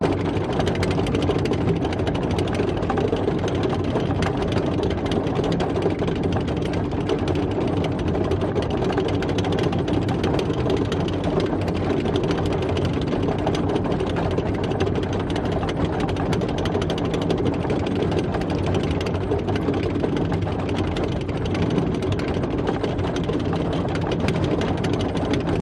Звук гусениц танка при движении зацикленный